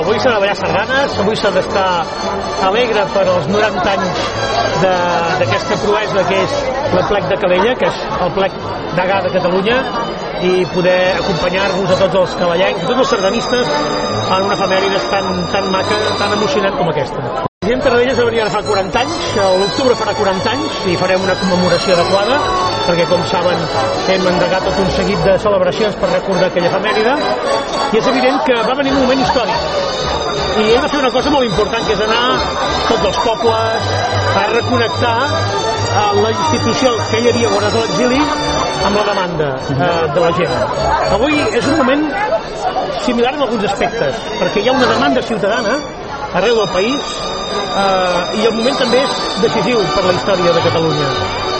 Entremig va concedir una entrevista a Ràdio Calella TV, en la qual va parlar de la seva condició de sardanista.